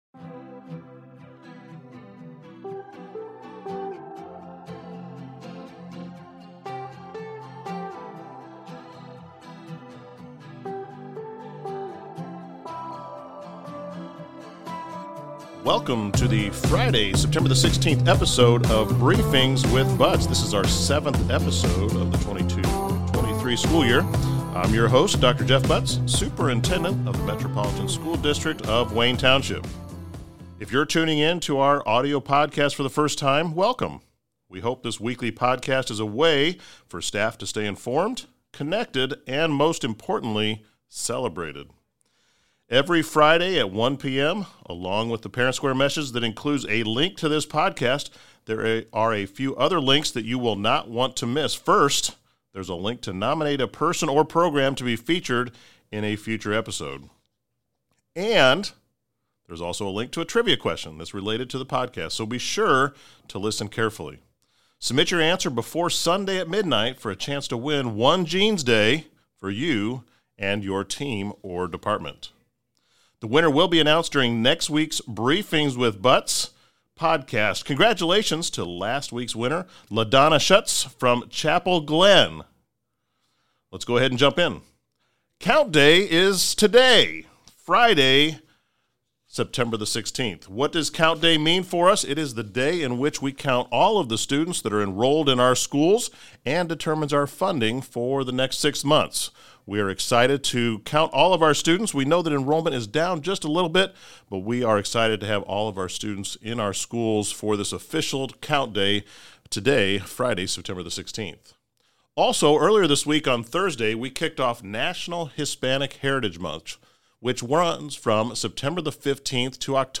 on location in the WBDG Studios.